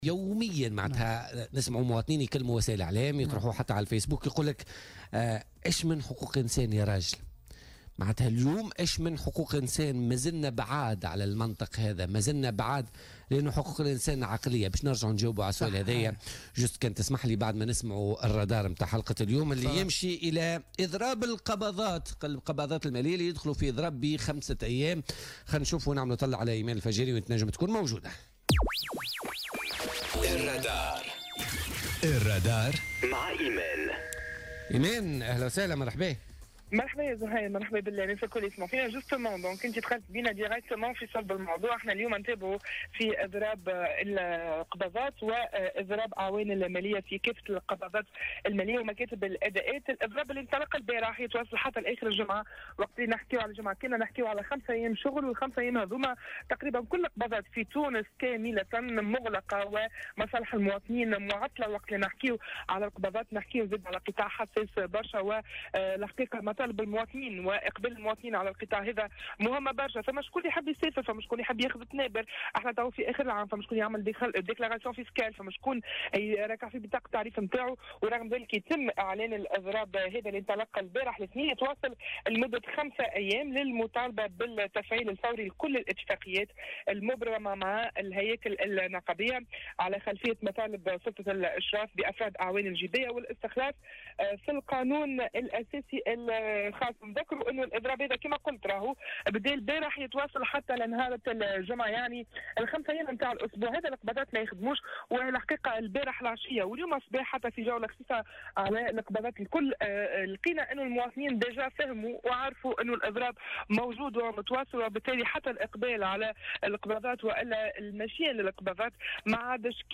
وقد عبر عدد من المواطنين في تصريح للجوهرة "اف ام" عن استيائهم من هذا الإضراب الذي عطلهم عن قضاء مصالحهم المستعجلة والذي سيستمر حتى نهاية هذا الأسبوع أي يوم الجمعة وبإحتساب عطلة نهاية الأسبوع فإن القباضات المالية لن تستأنف عملها إلا يوم الإثنين القادم.